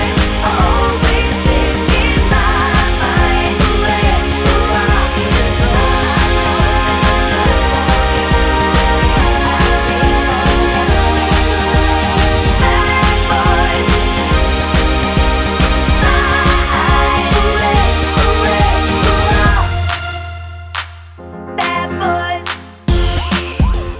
With Rapper Pop (2010s) 3:27 Buy £1.50